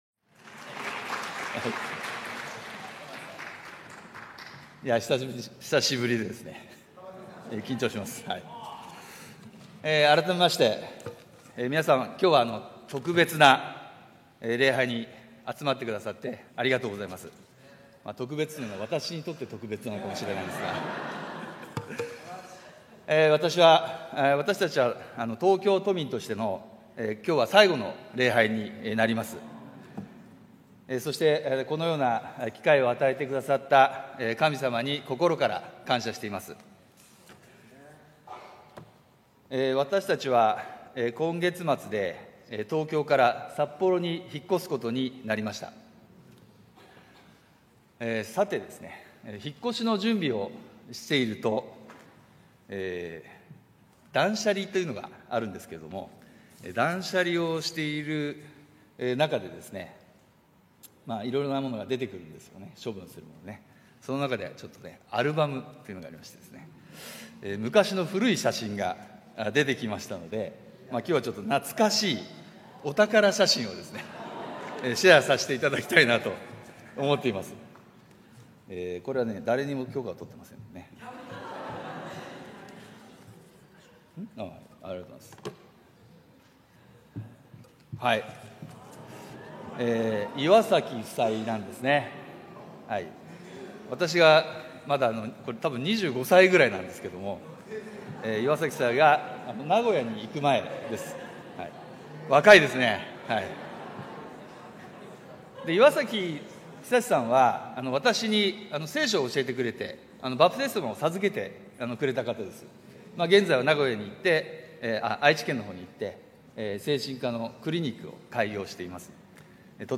日曜礼拝説教「神様は倦むことがない